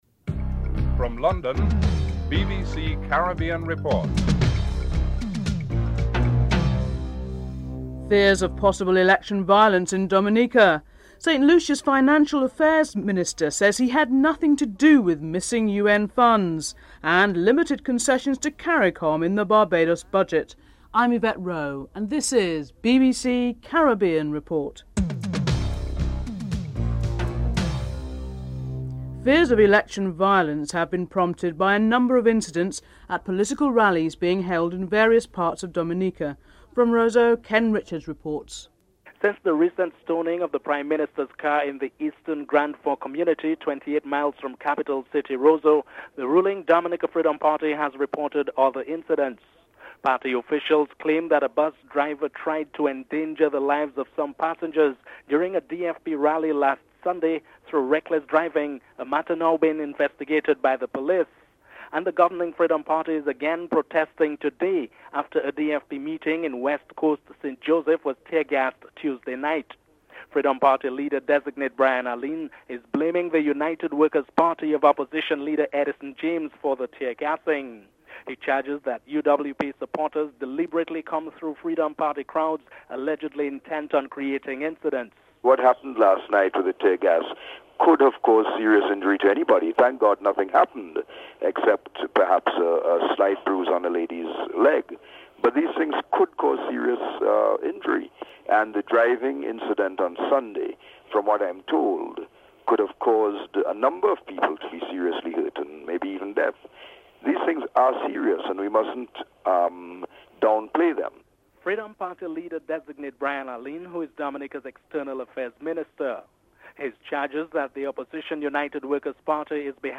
Fears of possible election violence in Dominica after a number of incidents at political rallies. Dominica Freedom Party Leader Brian Alleyne and Opposition Leader Edison James comment on these incidents.
8. Recap of top stories (15:05-15:21)